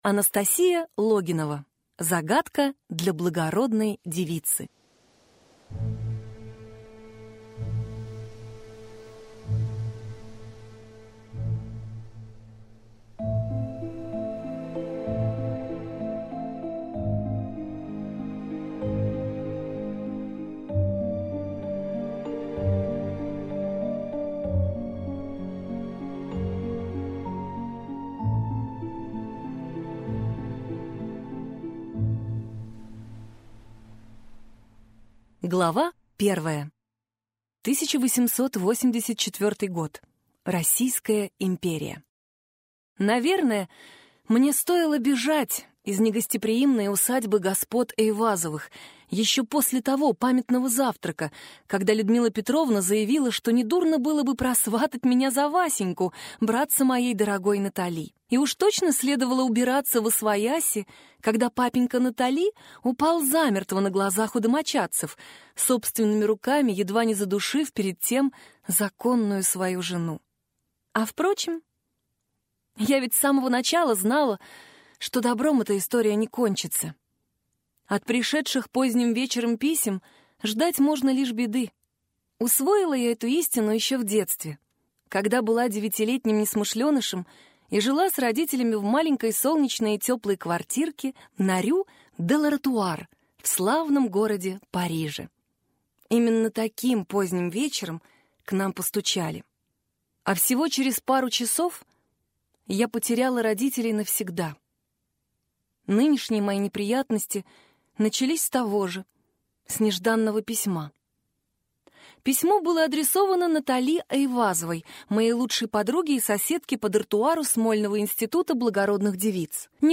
Аудиокнига Загадка для благородной девицы | Библиотека аудиокниг